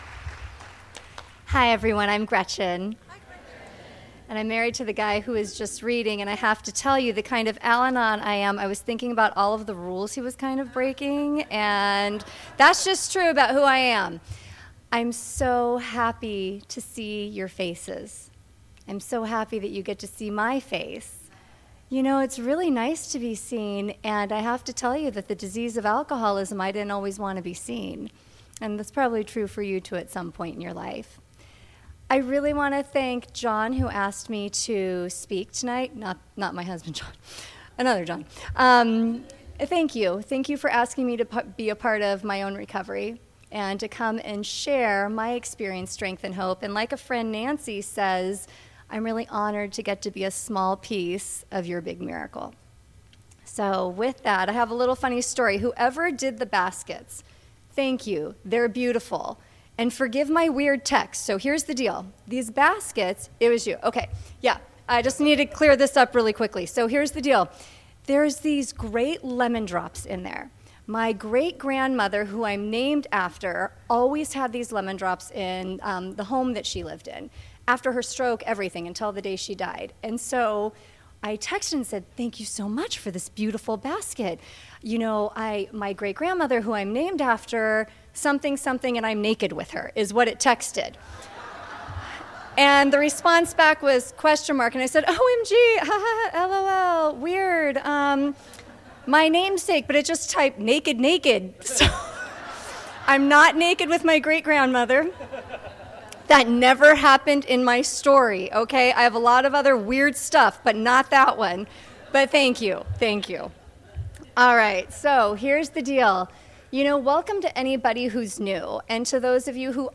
45th Southern California Al-Anon Family Groups Convention